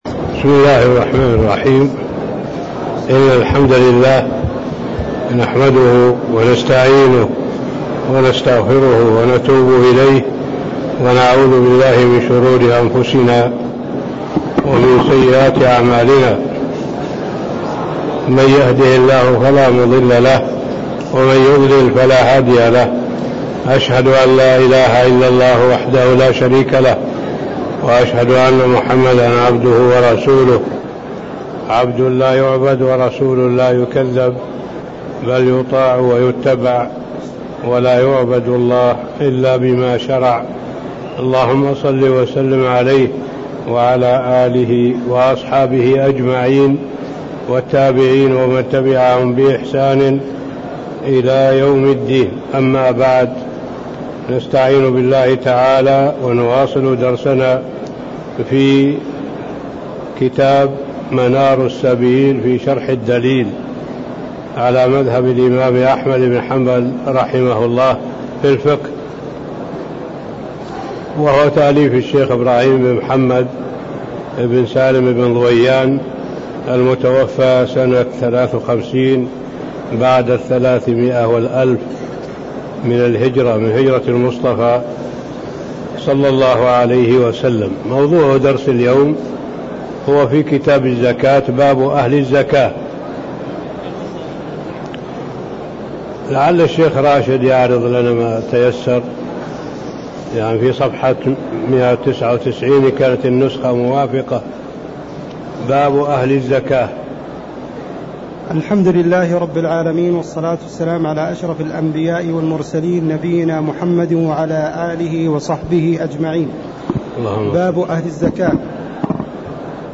تاريخ النشر ١٣ شعبان ١٤٣٦ هـ المكان: المسجد النبوي الشيخ